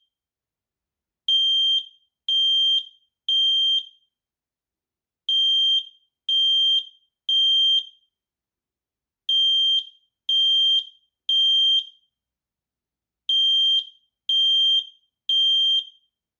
• Lautstärke im Test: 99,5 dBA
x-sense-sd19-w-funkrauchmelder-alarm.mp3